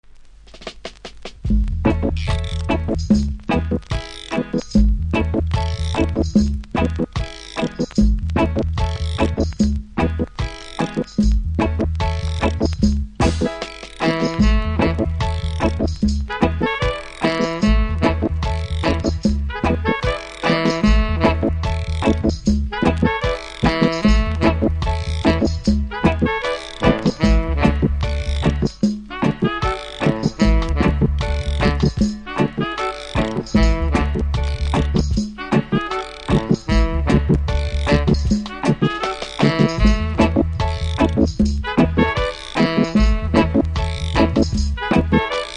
インスト好きには裏面をオススメします。